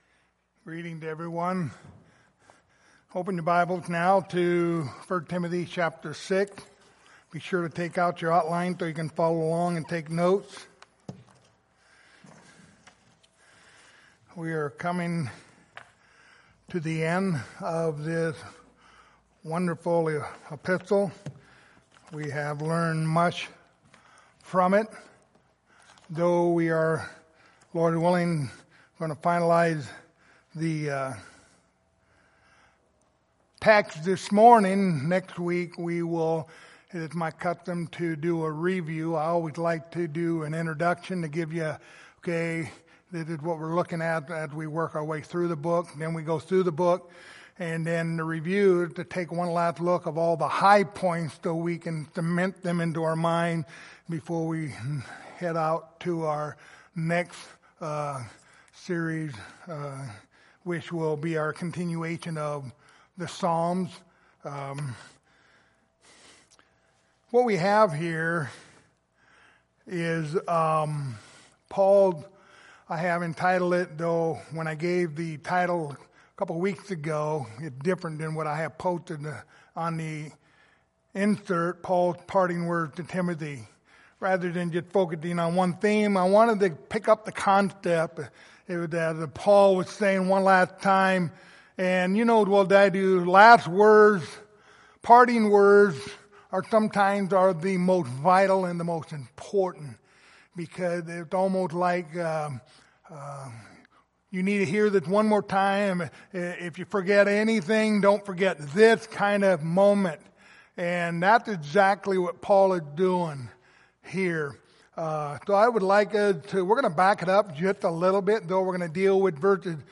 Passage: 1 Timothy 6:20-21 Service Type: Sunday Morning